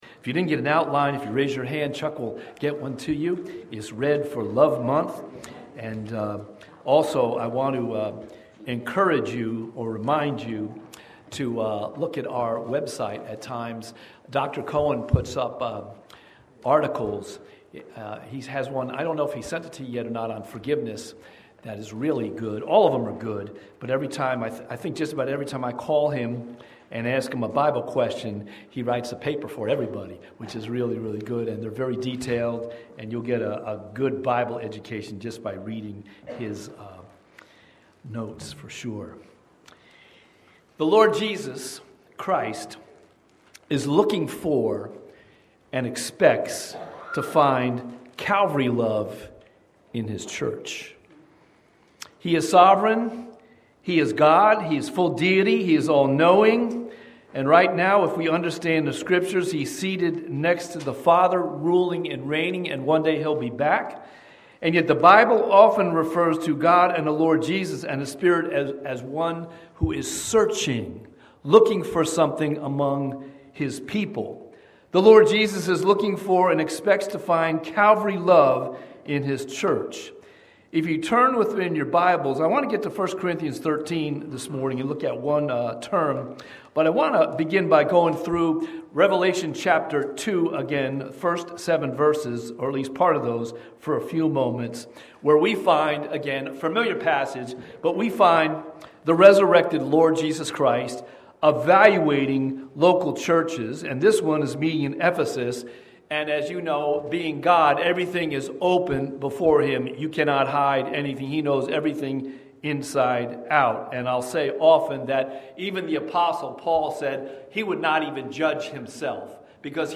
Watch Online Service recorded at 9:45 Sunday morning.
Sermon Audio